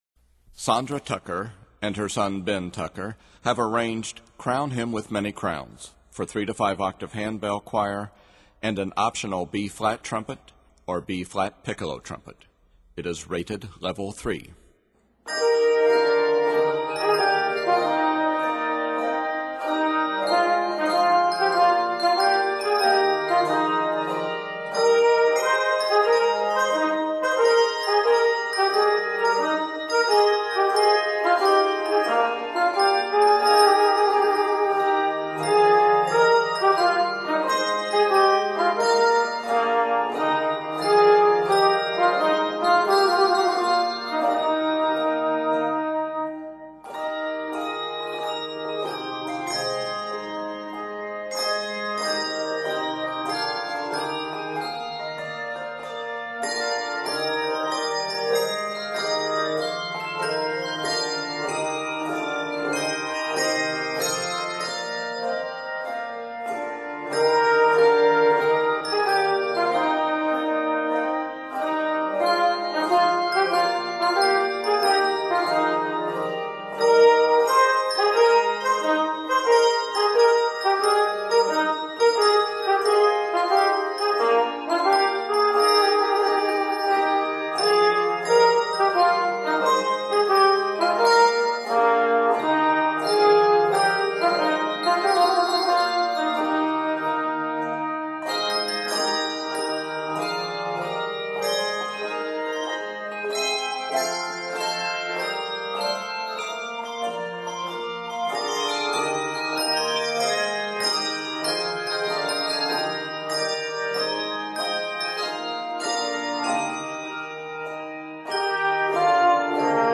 festive bell arrangement